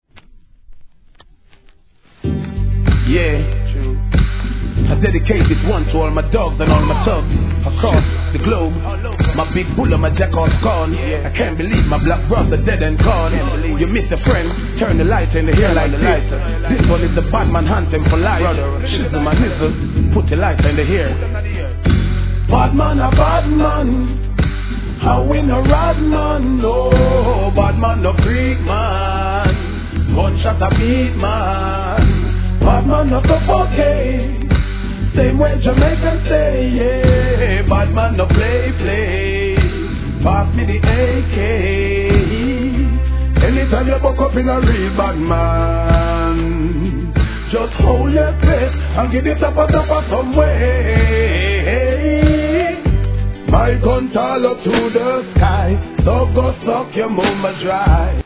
REGGAE
中盤からRHYTHMを変える2002年の大ヒット･チュ〜ン!!